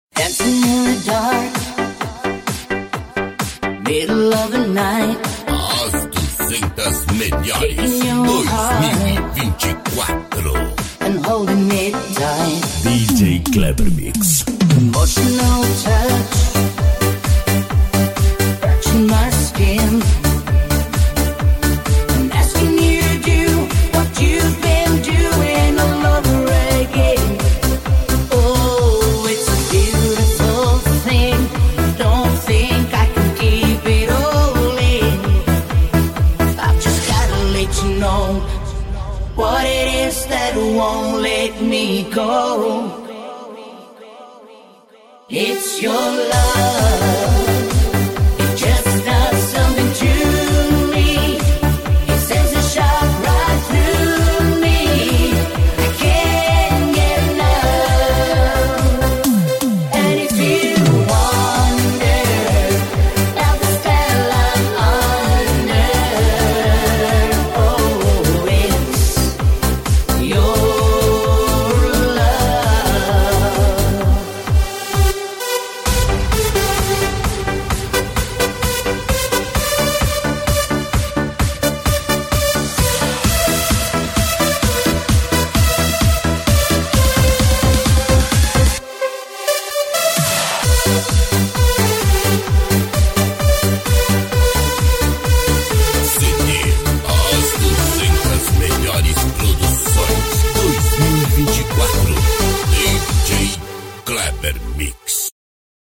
Dubai deira abra sound effects free download